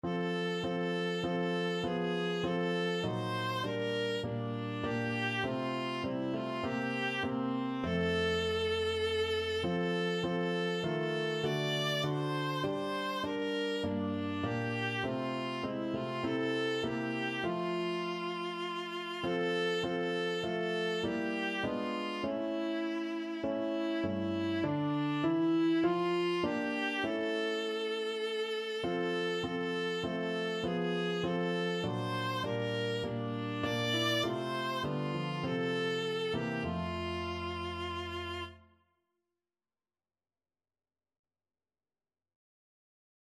Viola
"O Little Town of Bethlehem" is a popular Christmas carol.
F major (Sounding Pitch) (View more F major Music for Viola )
4/4 (View more 4/4 Music)